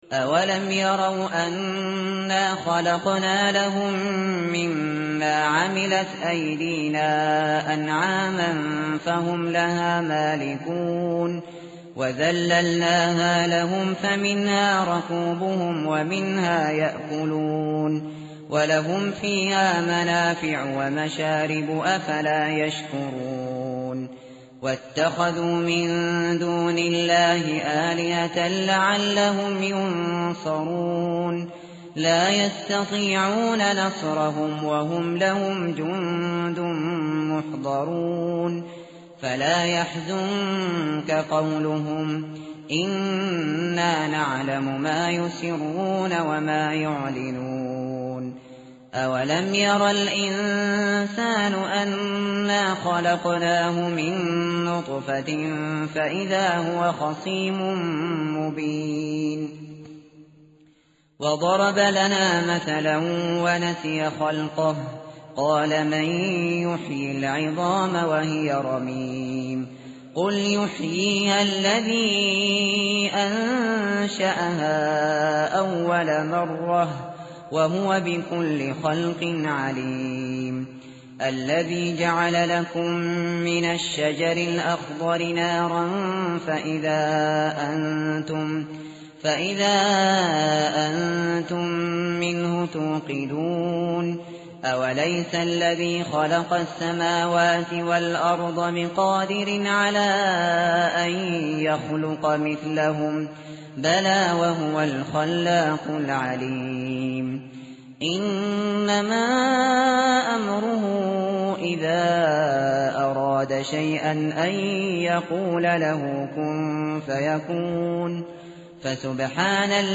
متن قرآن همراه باتلاوت قرآن و ترجمه
tartil_shateri_page_445.mp3